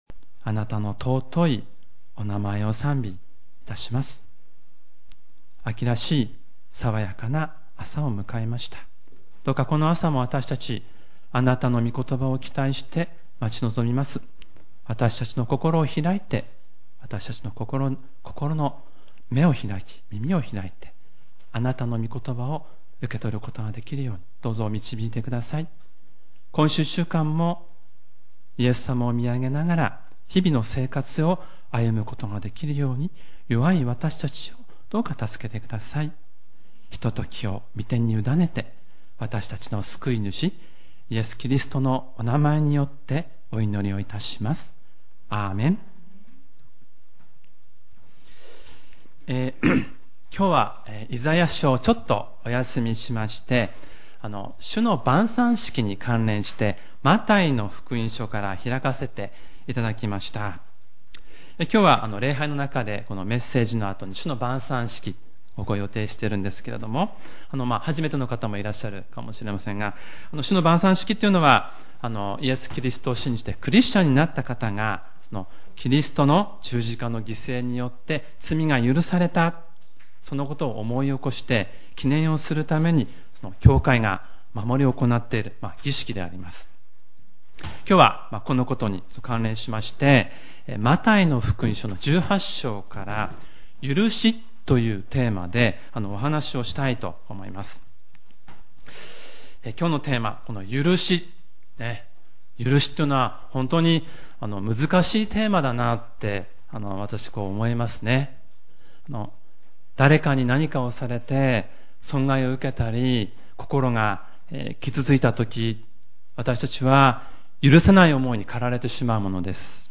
●主日礼拝メッセージ